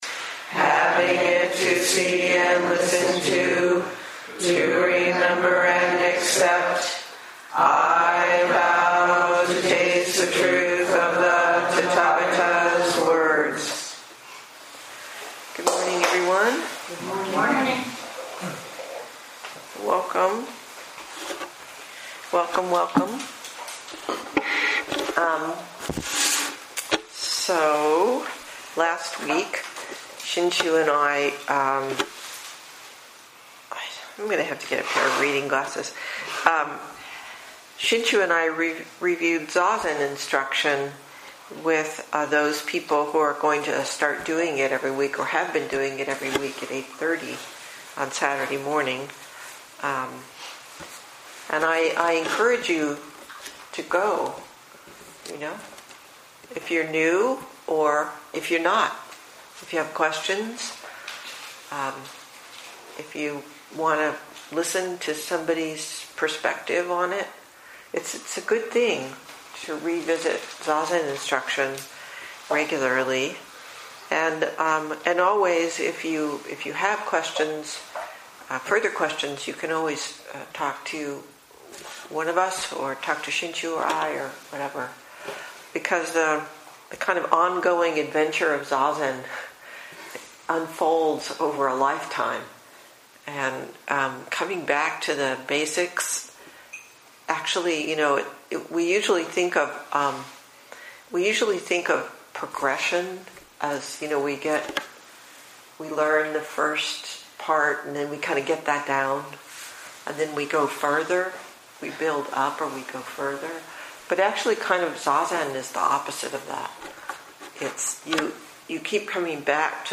On Zazen, Lecture at OGZC